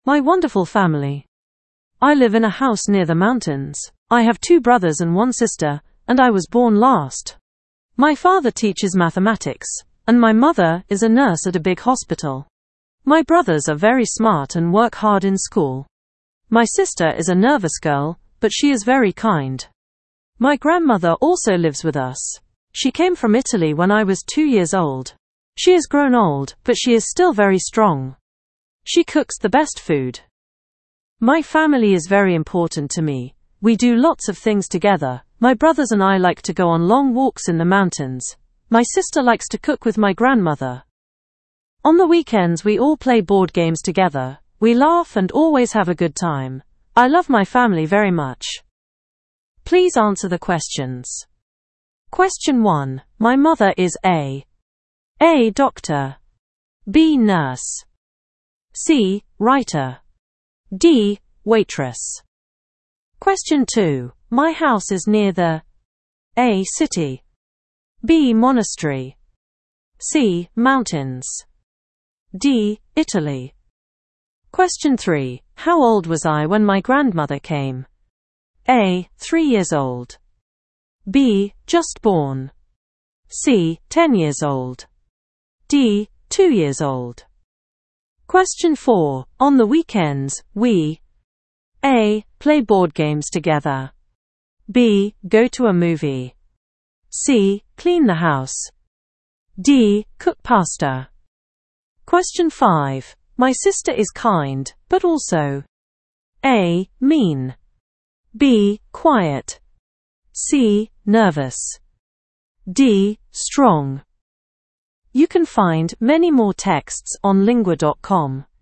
Inglaterra